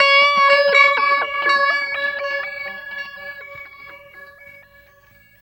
43 GUIT 2 -R.wav